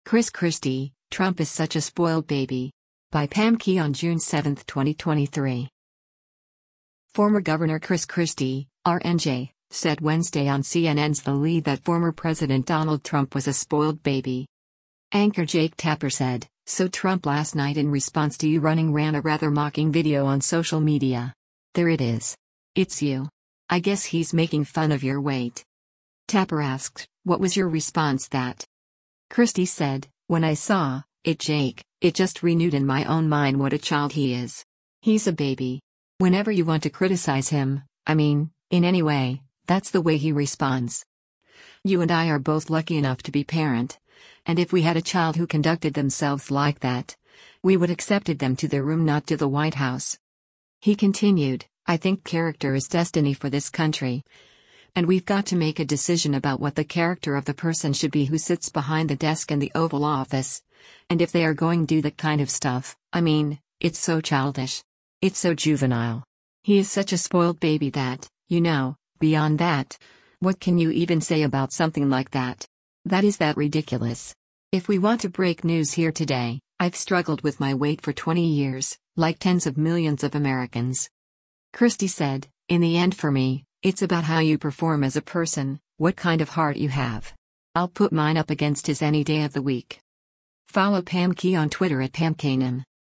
Former Gov. Chris Christie (R-NJ) said Wednesday on CNN’s “The Lead” that former president Donald Trump was a “spoiled baby.”